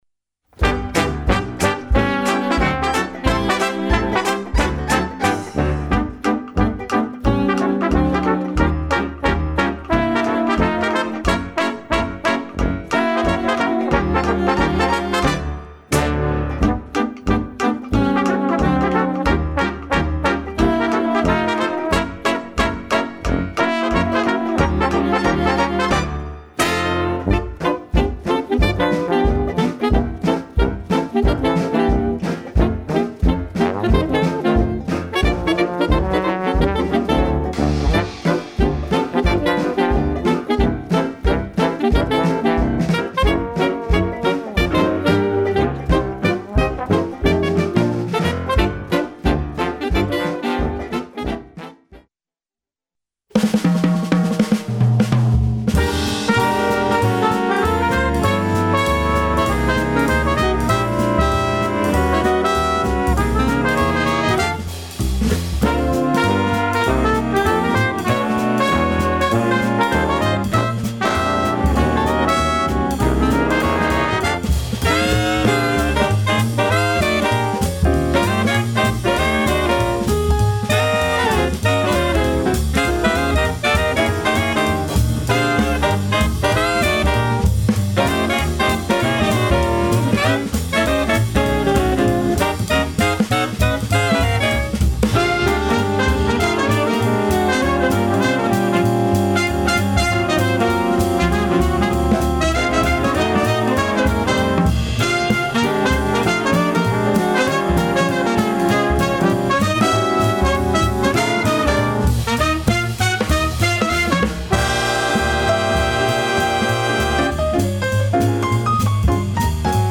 en concert
Grand Théâtre d'Angers à 20h30 – Le Centenaire du Jazz
trompettes
saxophones, clarinettes
soubassophone
banjo
batterie
euphonium
contrebasse